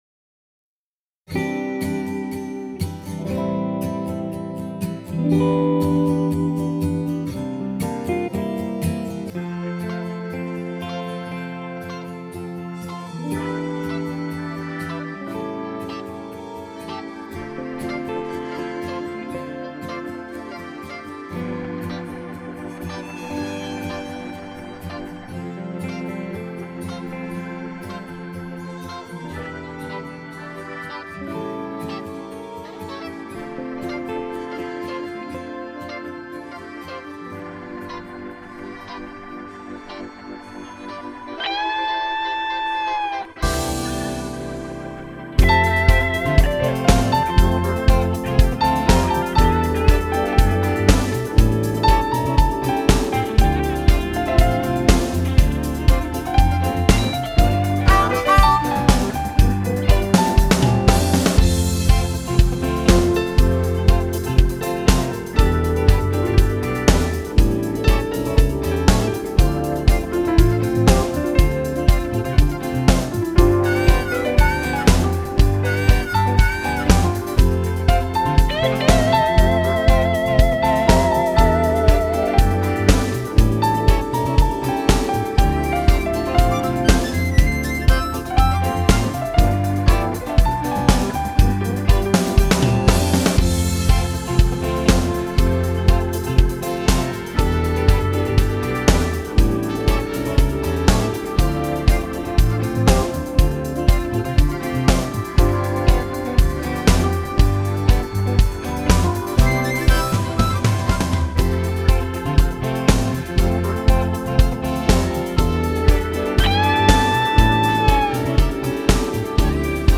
инстр. музыка